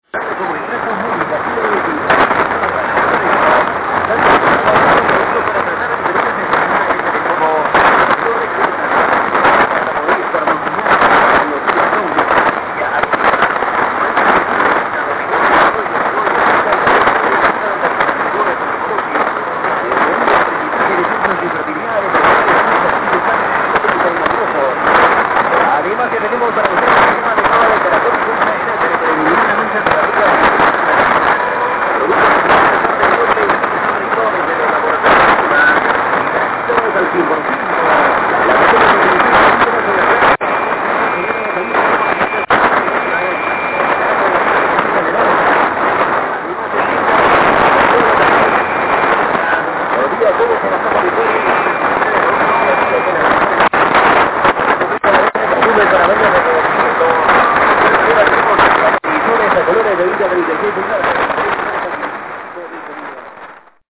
RGP3 Loop de Ferrite